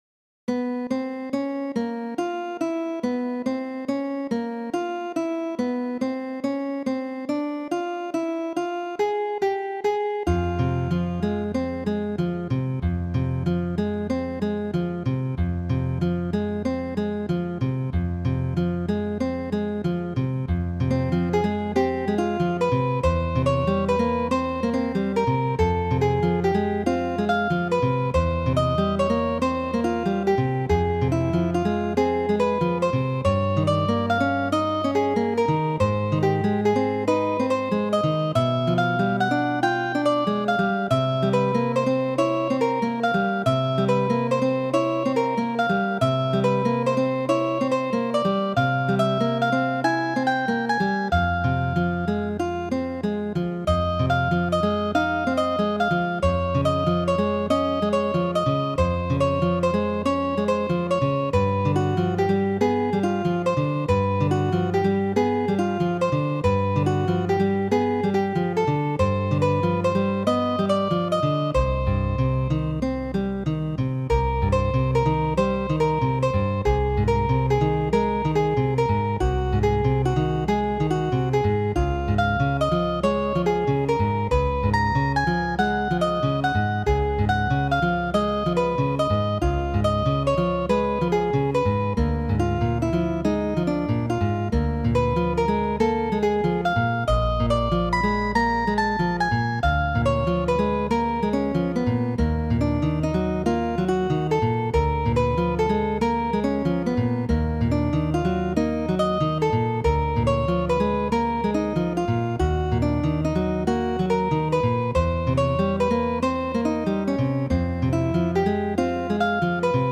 Studo por du manoj, de Federiko Chopin.